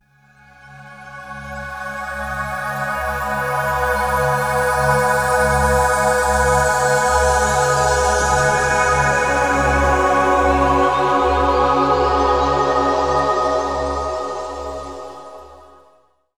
HEAVENLY.wav